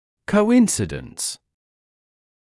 [kəu’ɪnsɪdəns][коу’инсидэнс]совпадение